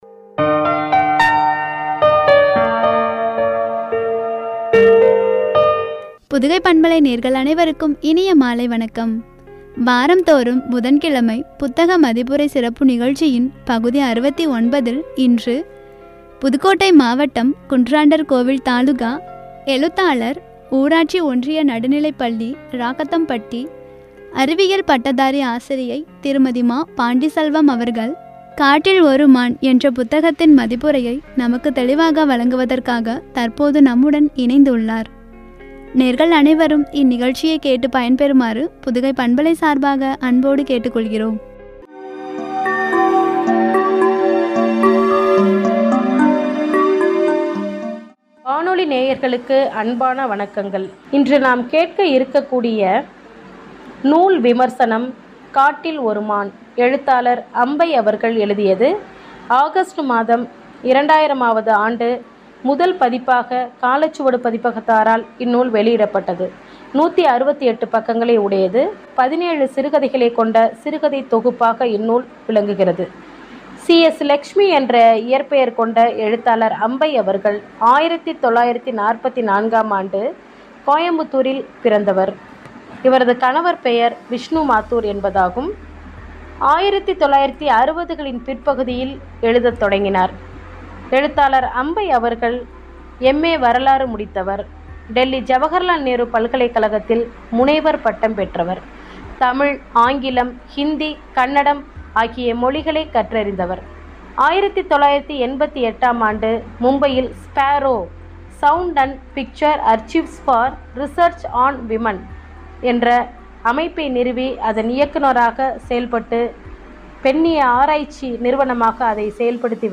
“காட்டில் ஒரு மான்” புத்தக மதிப்புரை (பகுதி -69), குறித்து வழங்கிய உரை.